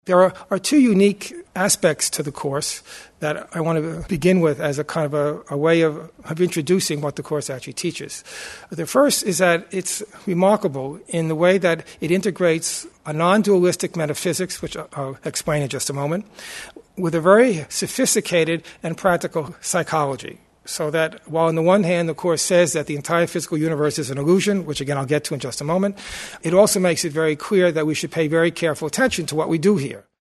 This lecture is a succinct introduction to A Course in Miracles, primarily meant for those who are new to it, yet also geared to those students who could benefit from such an overview.